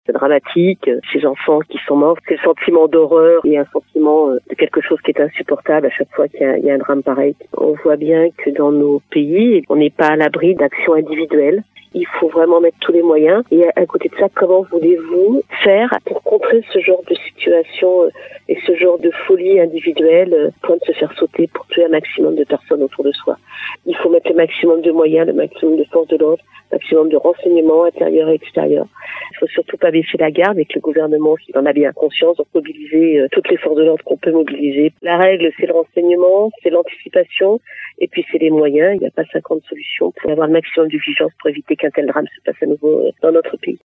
L’attentat revendiqué par l’Etat islamique pose encore une fois le problème de la sécurité des personnes face à des actions isolées et individuelles imprévisibles, comme le souligne Corinne Imbert, sénatrice de la Charente-Maritime :